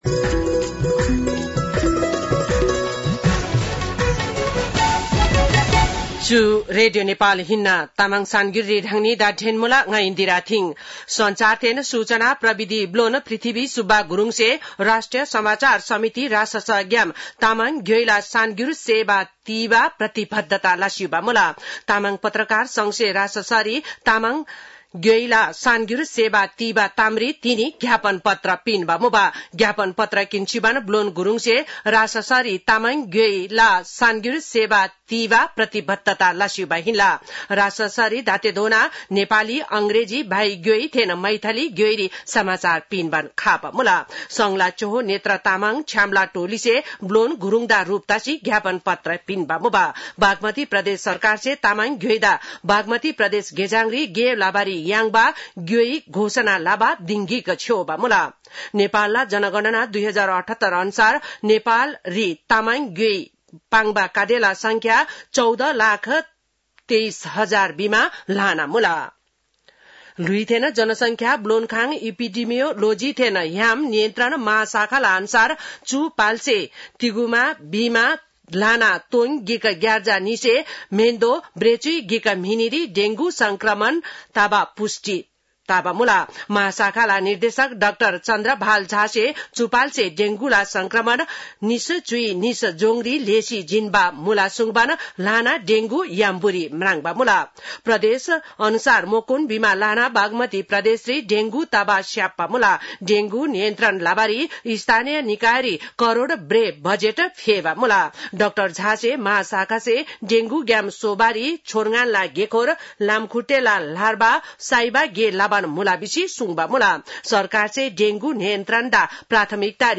तामाङ भाषाको समाचार : २८ जेठ , २०८२